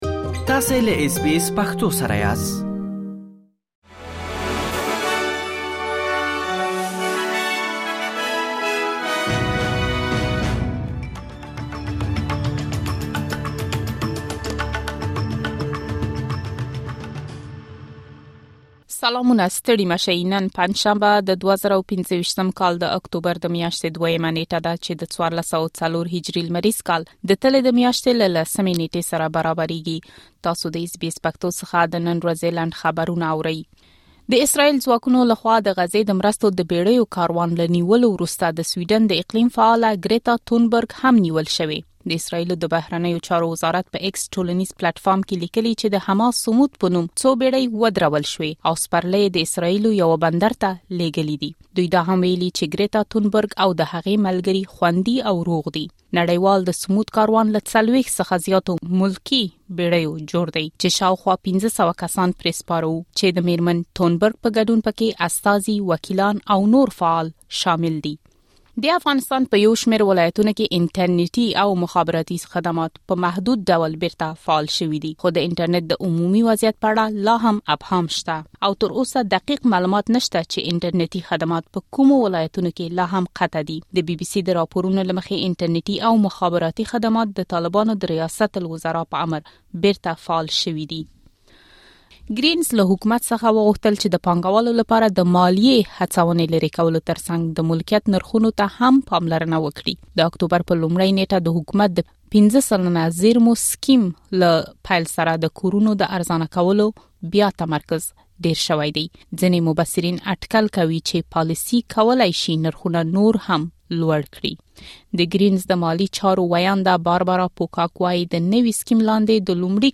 د اس بي اس پښتو د نن ورځې لنډ خبرونه|۲ اکتوبر ۲۰۲۵